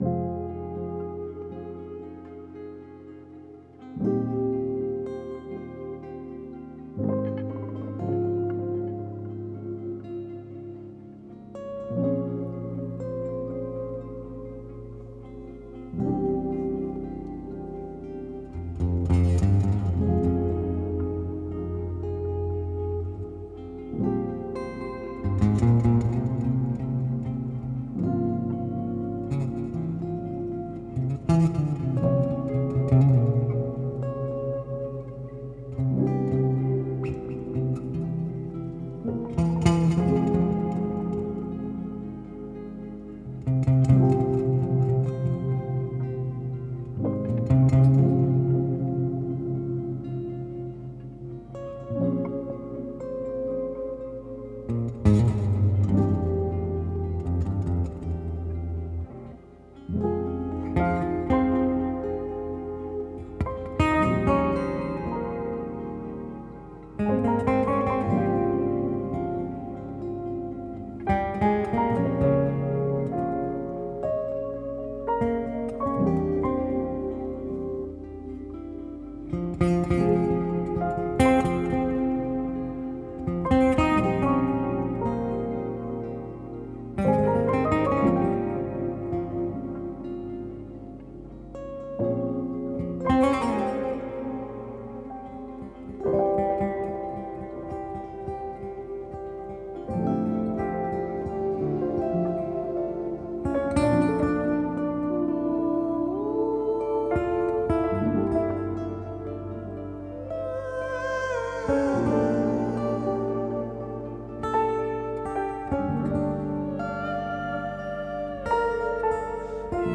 guitare oud
piano
fusionnent leurs talents dans un duo acoustique